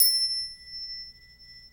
Index of /90_sSampleCDs/Roland L-CD701/PRC_Asian 2/PRC_Windchimes